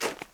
snow4.ogg